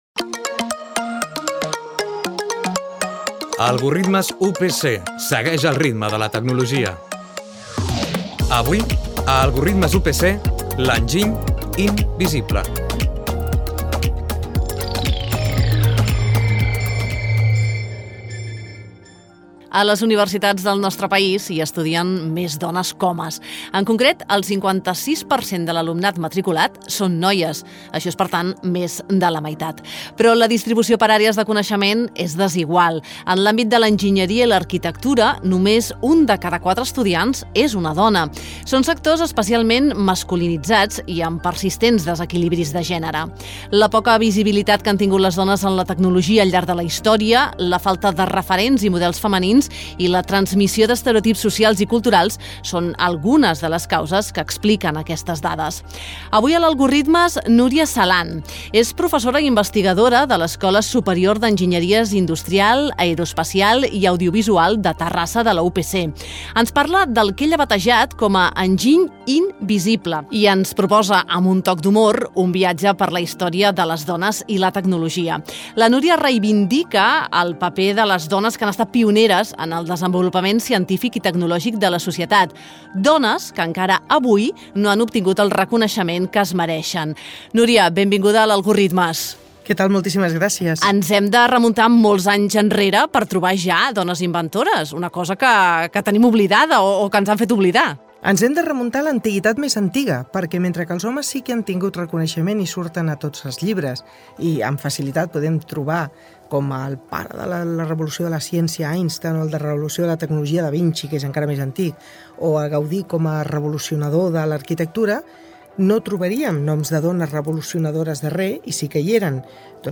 L'enginy invisible. Careta del programa, espai dedicat a la poca visibilitat de les dones a la tecnologia i la manca de dones tecnòlogues.
Divulgació